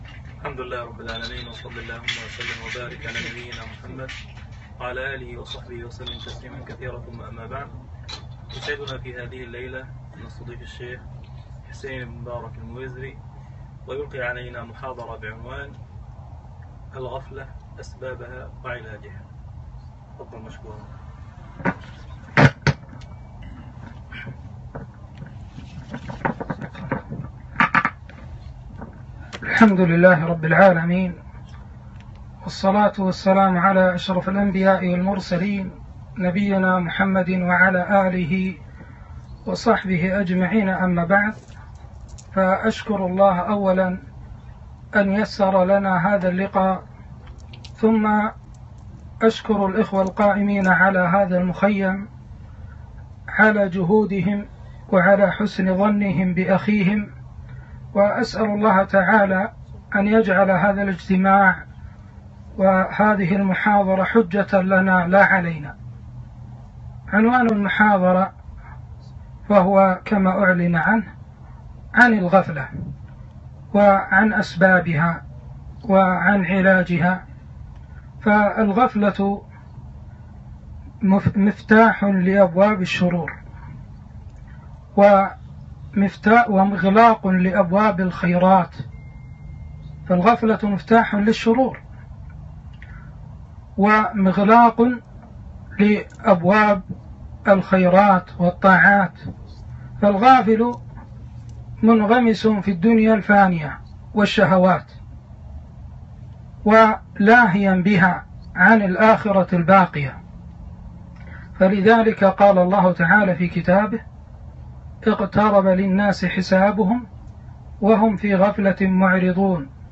محاضرة - الغفلة أسبابها وعلاجها